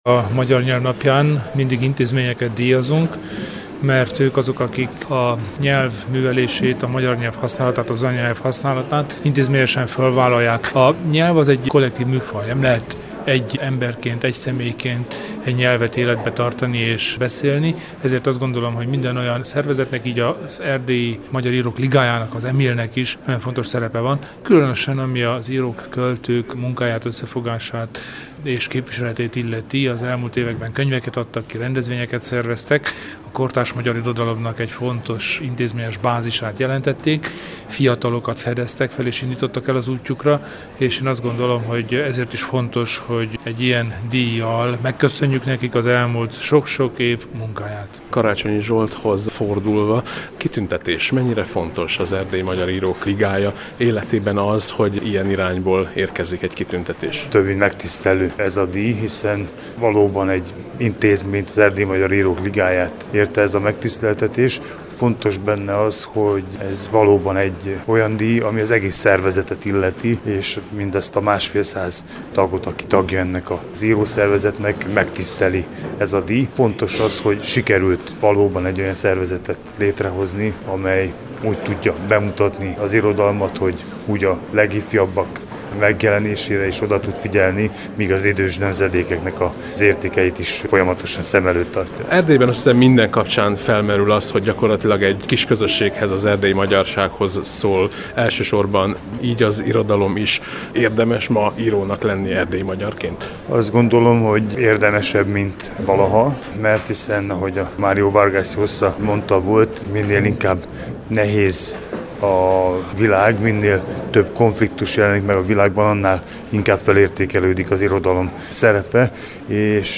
A Magyar Nyelv Napját ünnepelték Kolozsváron